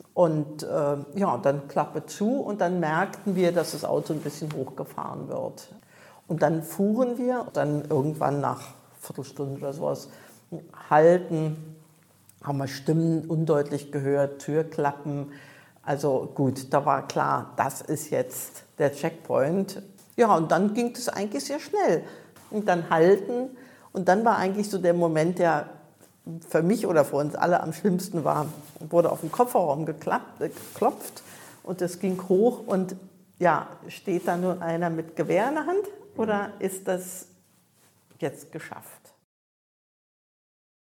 Reportage, Flucht, Fluchthilfe, DDR, Birma, Myanmar,
Die Frau im Kofferraum erinnert sich an die Fahrt: Ihr Browser kann dieses Tondokument nicht wiedergeben.